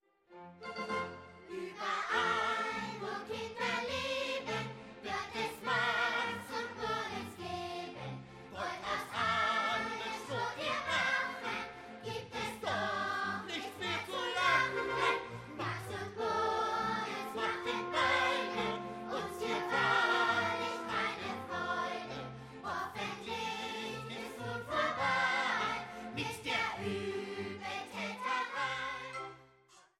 Konzerte für Sprecher und Orchester